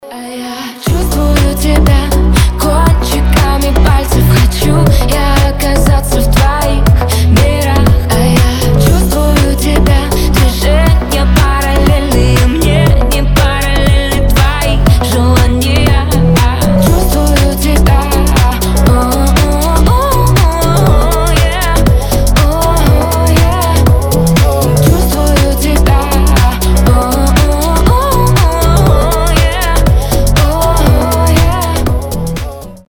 • Качество: 320, Stereo
поп
чувственные